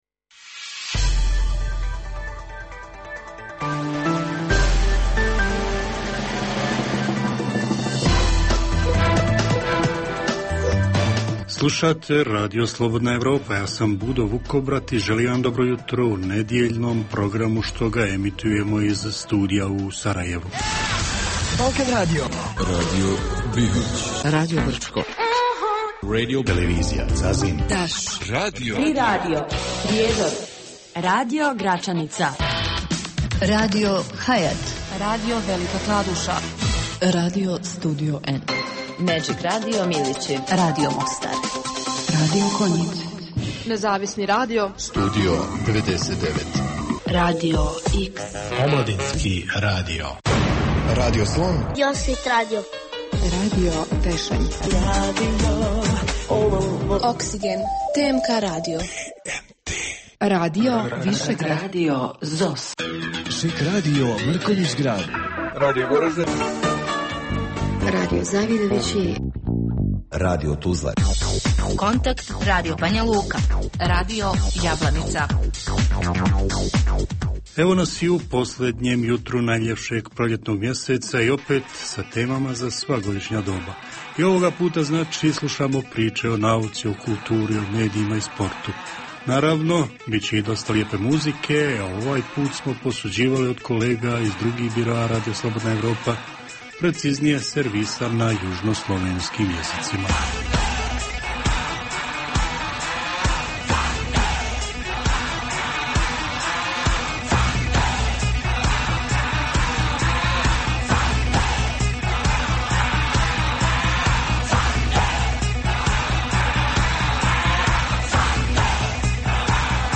Jutarnji program namijenjen slušaocima u Bosni i Hercegovini. Sadrži novosti iz svijeta nauke, medicine, visokih tehnologija, sporta, filma i muzike.